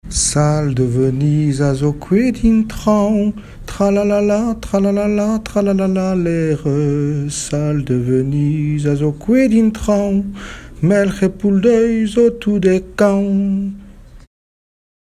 enfantine : comptine
Chansons populaires
Pièce musicale inédite